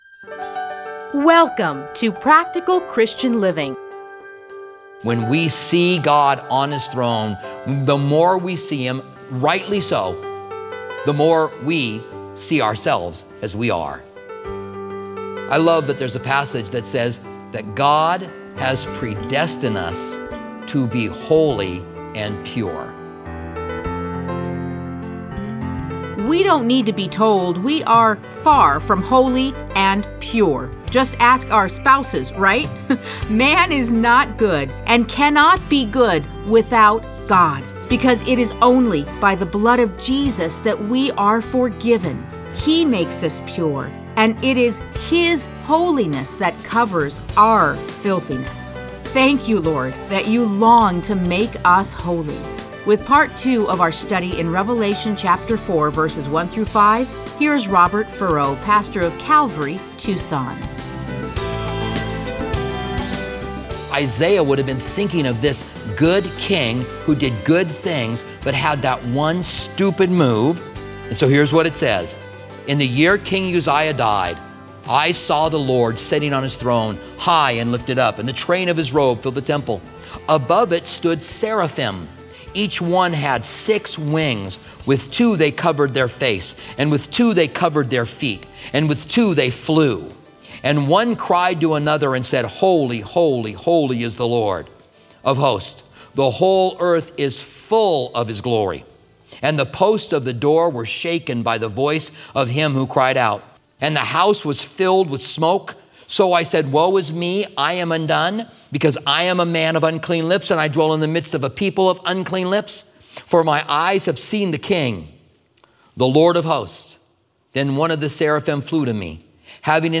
Listen to a teaching from Revelation 4:1-5.